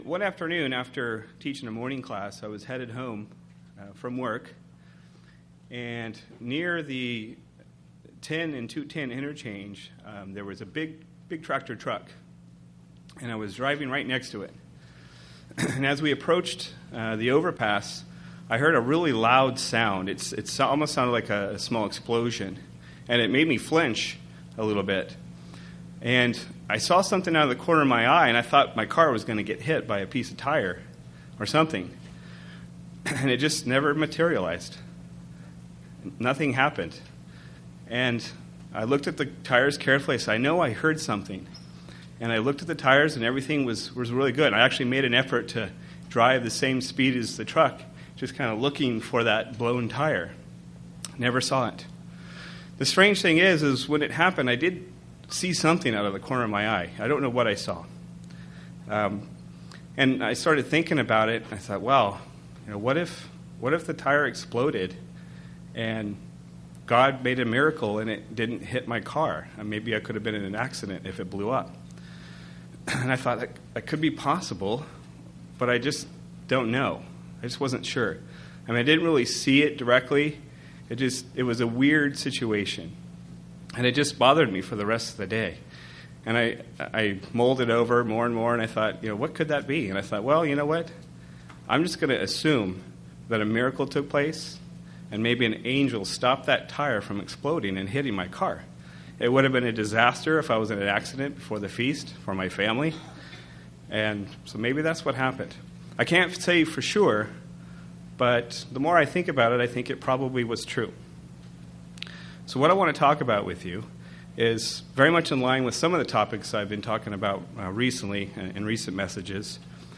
This a a sermon about angels being created beings. It has background information about angles including the duties of angles and what angels may look like.